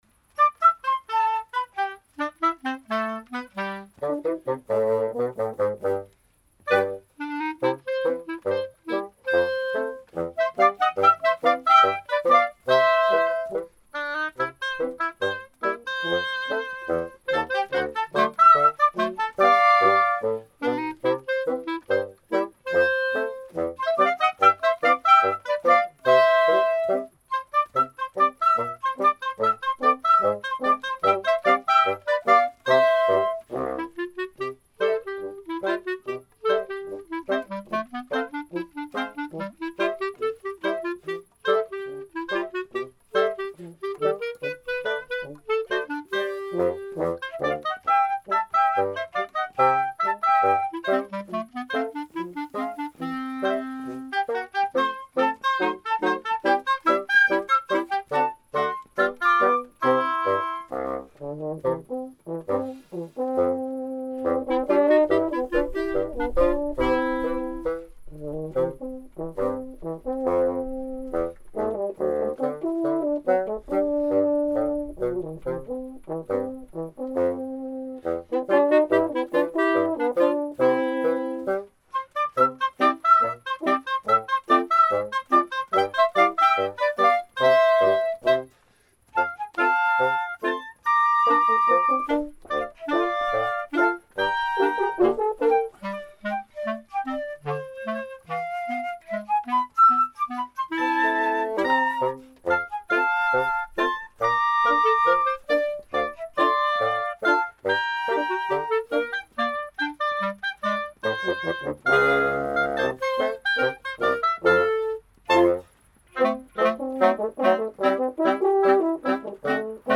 2020-08-05 Musik i Sommarkväll vid Mullhyttans kyrka med Örebro blåsarkvintett (utomhus konsert)
Örebro blåsarkvintett består av musiker från Örebro orkesterförening.
Flöjt
Oboe
Klarinett
Fagott
Horn
Inspelningen är gjord med en Zoom H4n Pro (24-bit/48KHz sampling)